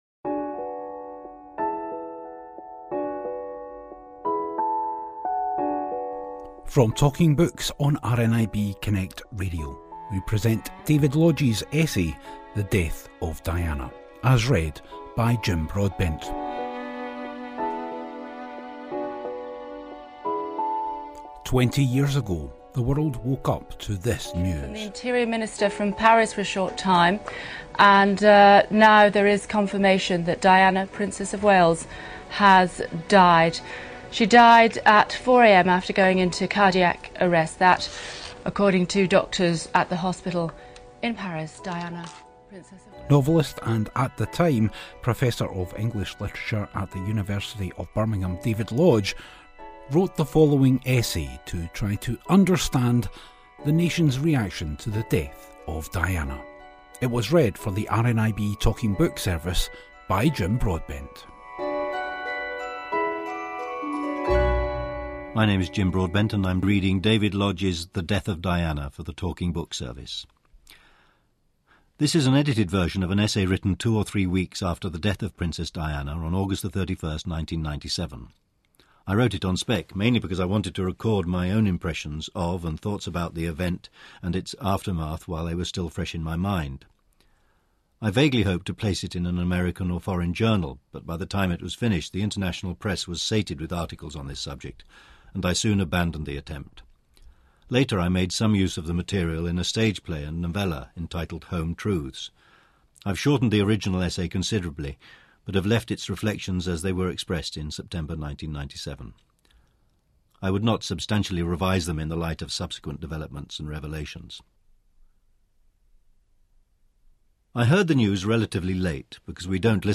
Read by Jim Broadbent exclusively for RNIB Talking Books, the Death of Diana by David Lodge is the author's reflections of Diana as a public figure and an attempt to understand the outpouring of grief felt 20 years ago.